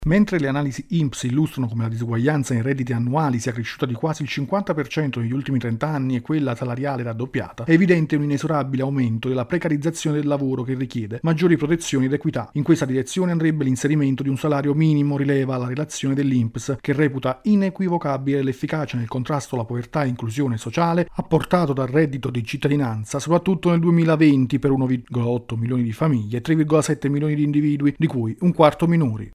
Economia